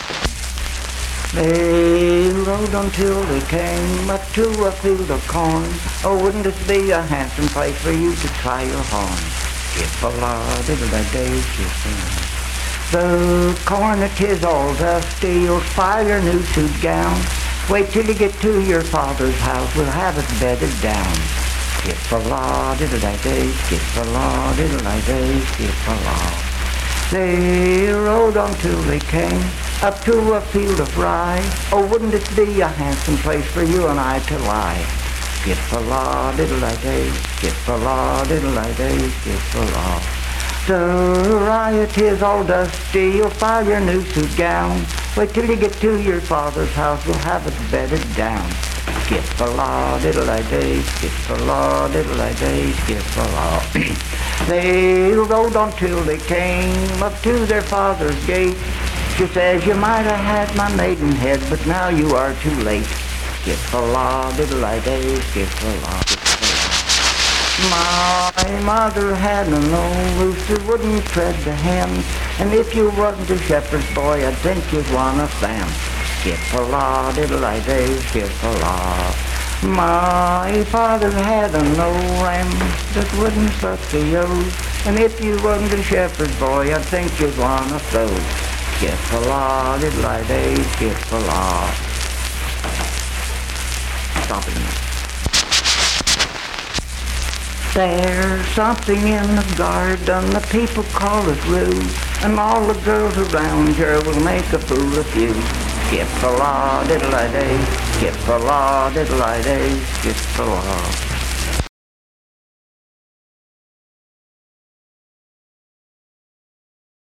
Unaccompanied vocal music performance
Bawdy Songs
Voice (sung)
Spencer (W. Va.), Roane County (W. Va.)